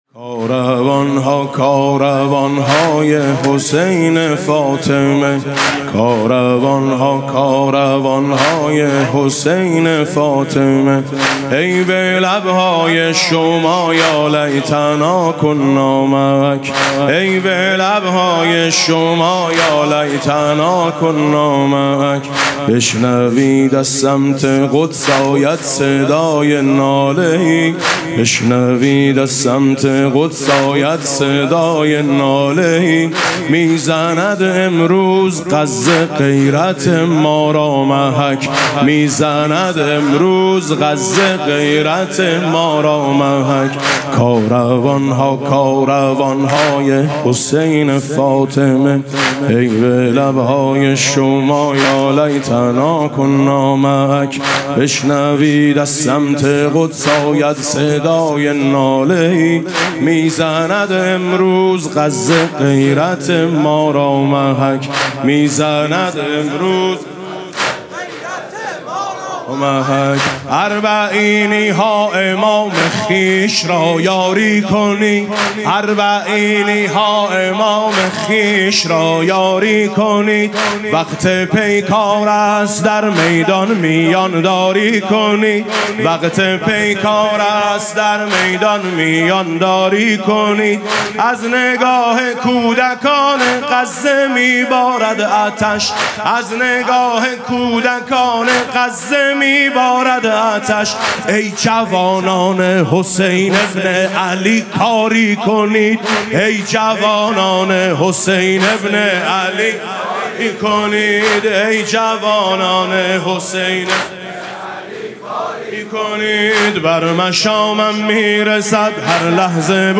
مسیر پیاده روی نجف تا کربلا [عمود ۹۰۹]
مناسبت: ایام پیاده روی اربعین حسینی
با نوای: حاج میثم مطیعی
از نگاه کودکان غزه می بارد عطش (واحد تند)